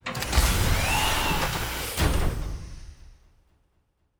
pgs/Assets/Audio/Sci-Fi Sounds/Doors and Portals/Door 1 Open 1.wav at master
Door 1 Open 1.wav